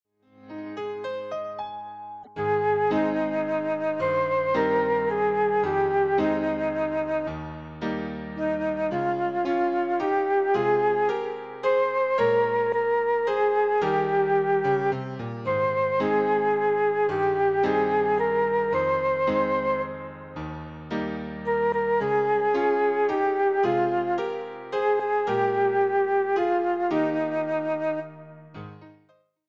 Neue Musik
Ensemblemusik
Duo
Stimme (1), Klavier (1)